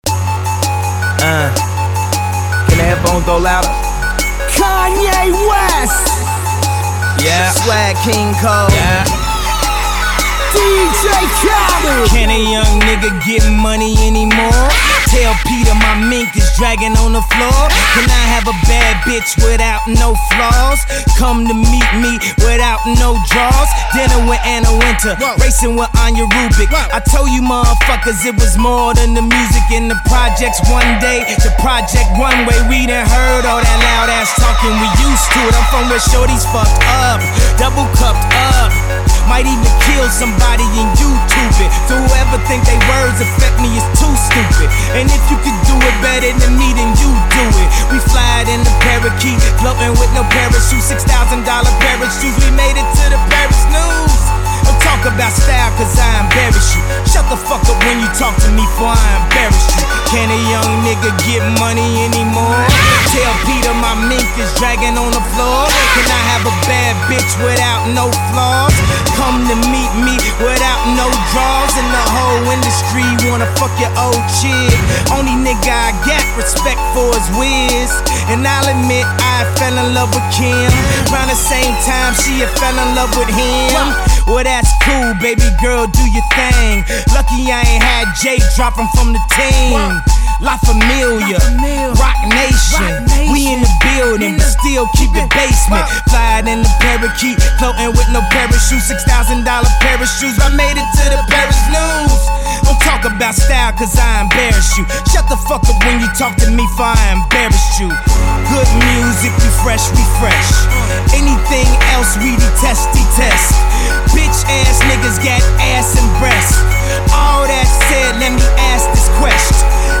Over computer blips and orchestra hits